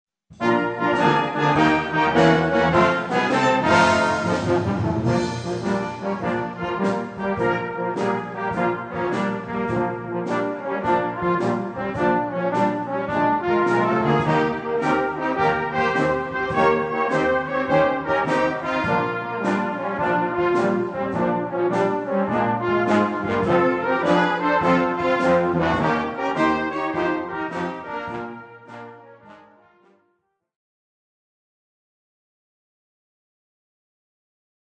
een eenvoudig, maar mooi concertwerkje voor jeugdorkest
hedendaagse populaire ritmes